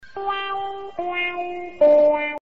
Звуки головокружения
Смешной эффект недопонимания